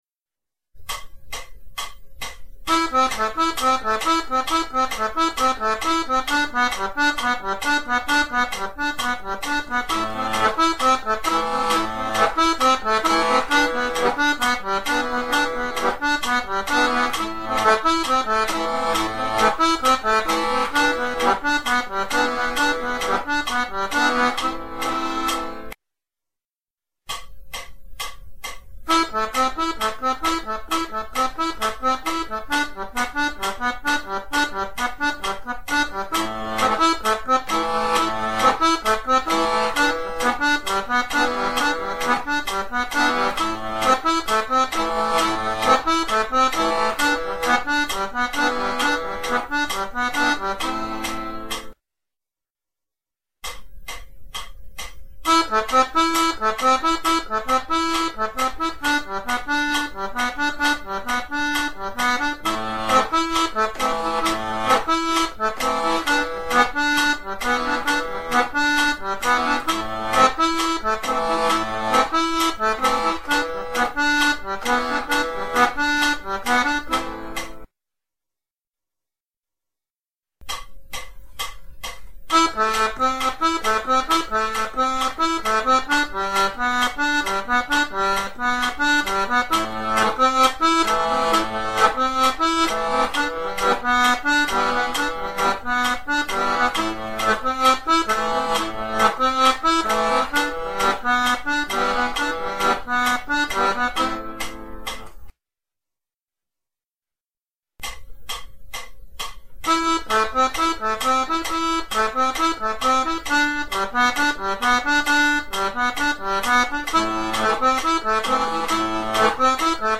Accordeon diatonique et Musiques Traditionnelles
Arpège : jeu consistant à faire entendre sucessivement les notes d'un accord
Arpèges 2 Temps
Le 3 3 2 , B et a en simultanés